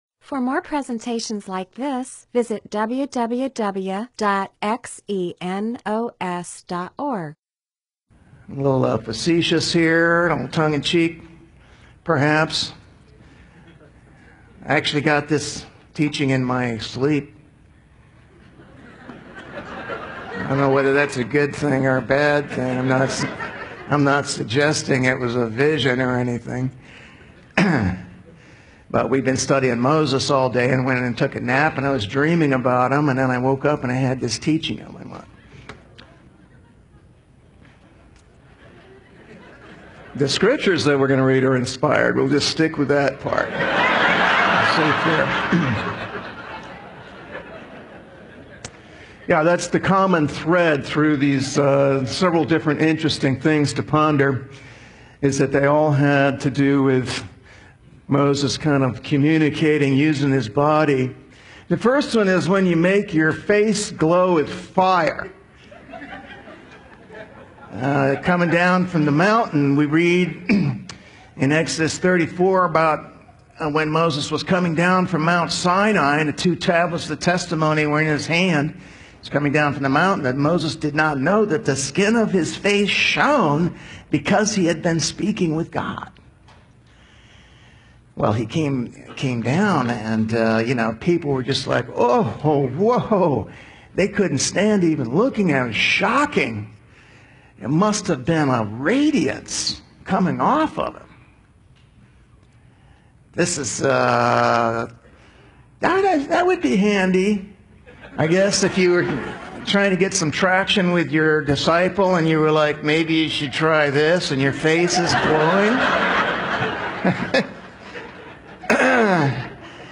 MP4/M4A audio recording of a Bible teaching/sermon/presentation about .
2015 Servant Team Retreat